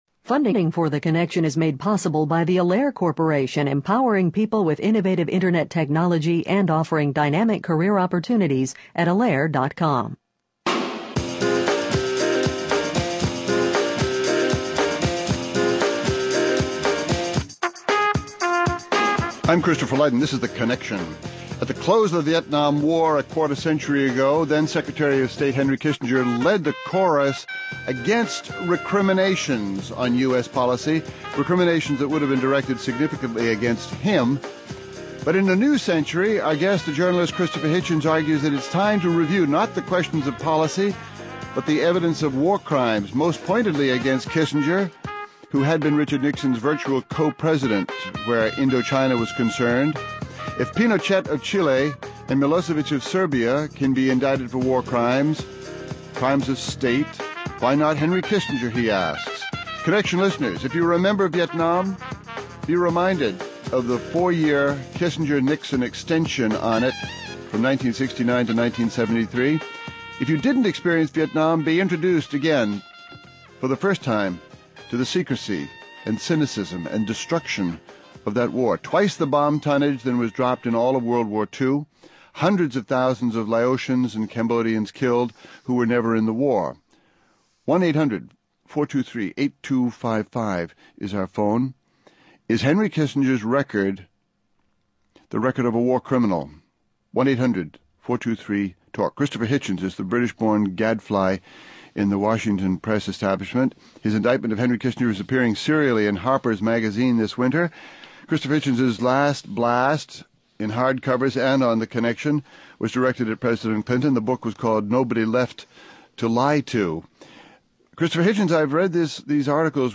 The journalist Christopher Hitchens and his case against Henry Kissinger are here. (Hosted by Christopher Lydon)